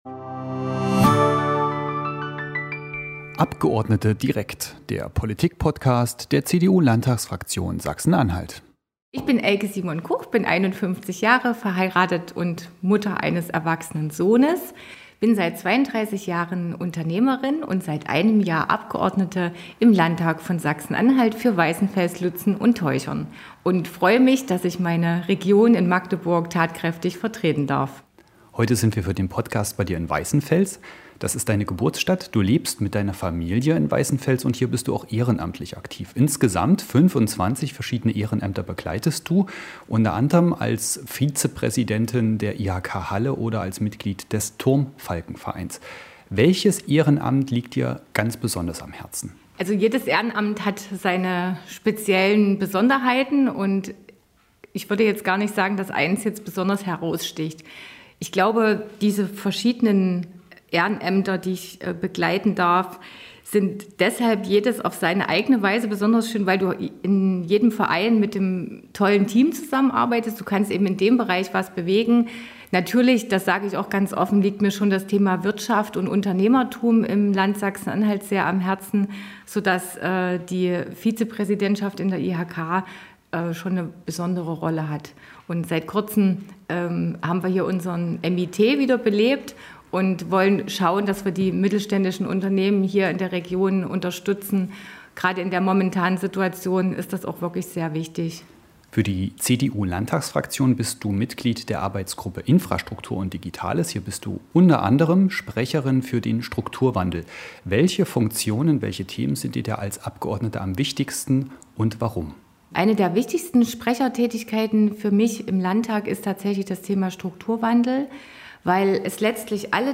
Im Podcast spricht sie über das